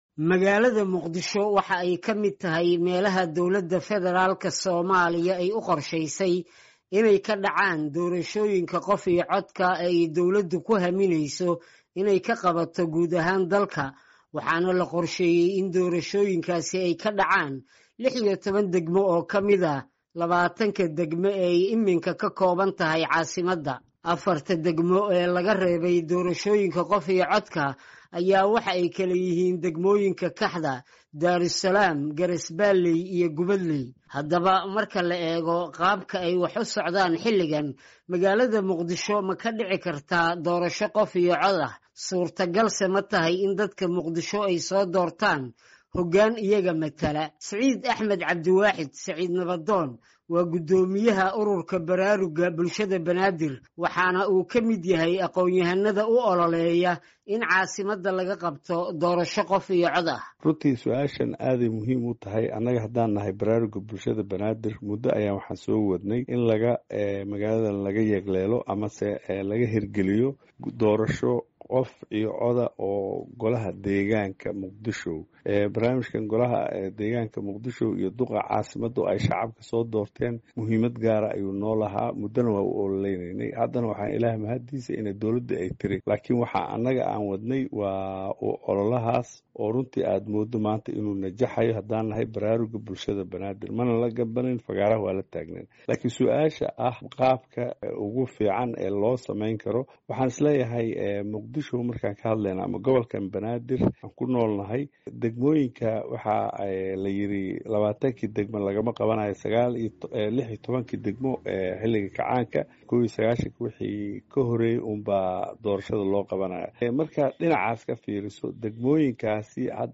War Deg-Deg ah